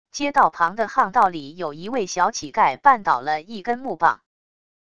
街道旁的巷道里有一位小乞丐绊倒了一根木棒wav音频